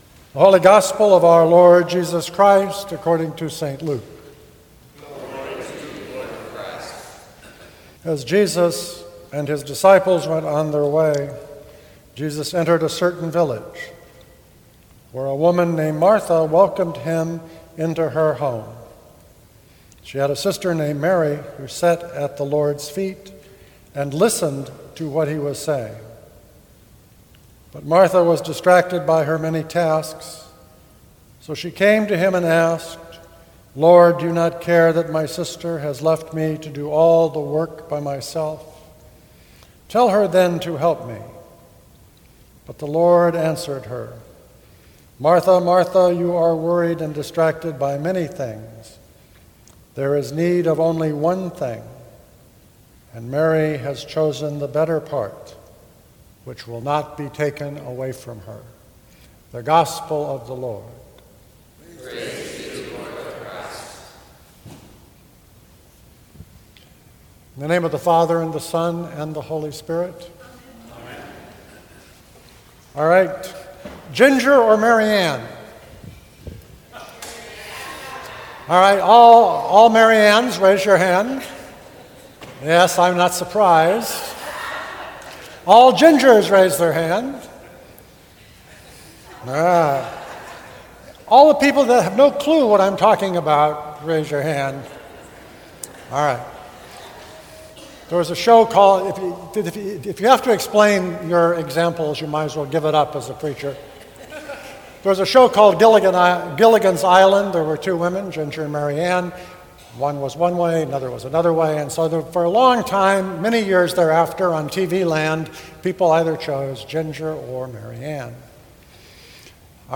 Sermons from St. Cross Episcopal Church